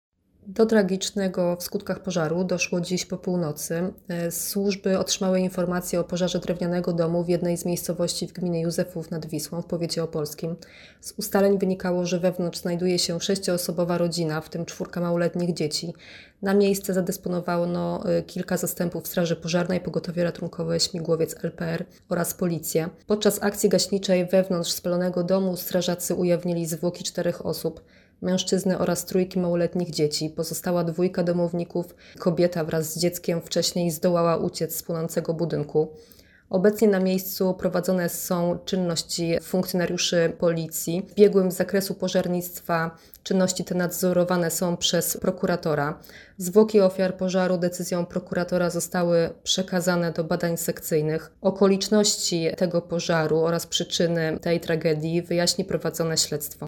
Nagranie audio Wypowiedź